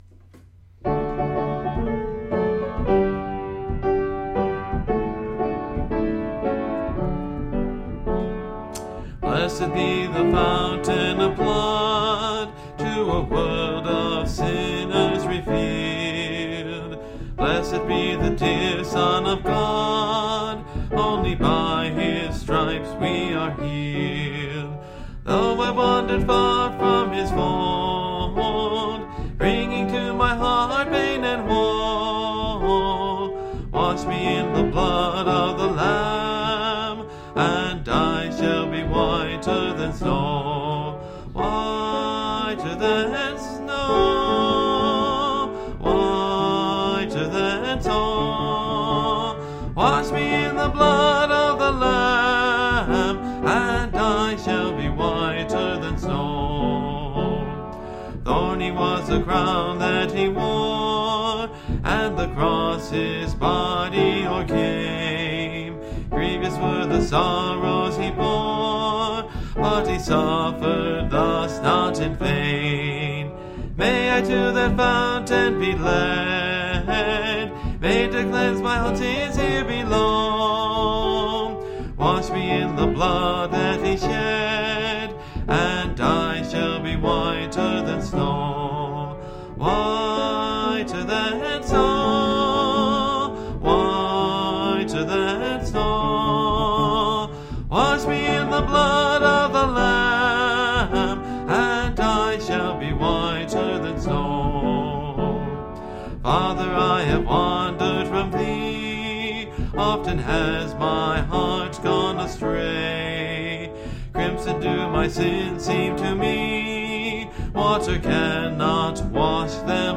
(Part of a series singing through the hymnbook I grew up with: Great Hymns of the Faith)